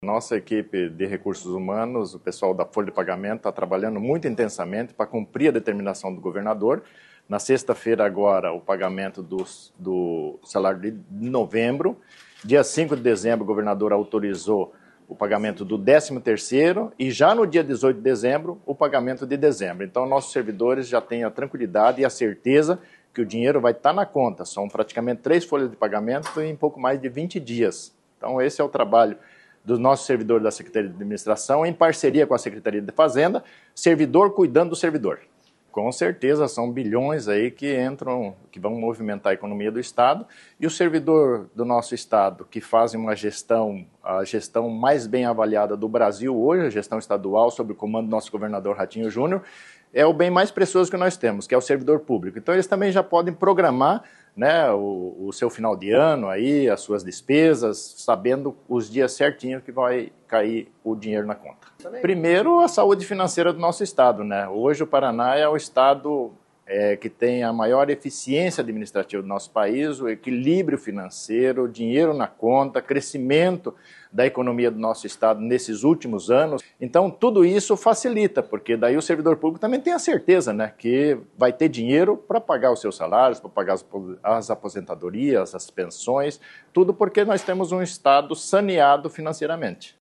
Sonora do secretário da Administração e da Previdência, Luizão Goulart, sobre o pagamento do 13º salário integral no dia 5 de dezembro